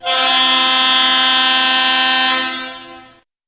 Звуки грузовика, фуры
Гудок грузовика